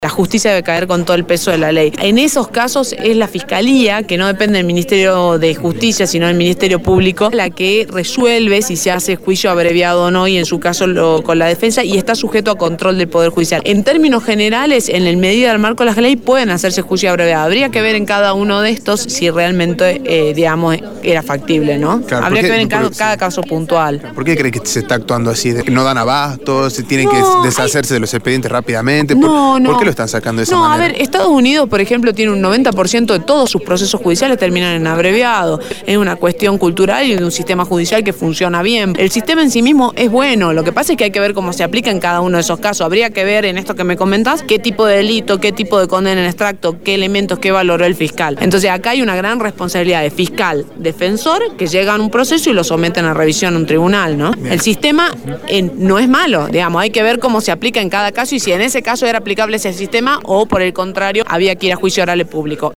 “La justicia debe caer con todo el peso de la ley, en esos casos es la fiscalía que no depende del Ministerio de Justicia sino el Ministerio Público, es la que resuelve si se hace juicio abreviado o no y en su caso con la defensa y está sujeto a control del Poder Judicial, en términos generales en la medida del marco de la ley pueden hacerse juicios abreviados, habría que ver en cada uno de estos sí es realmente es factible, en cada caso puntual”, sostuvo Calleti a Radio Dinamo.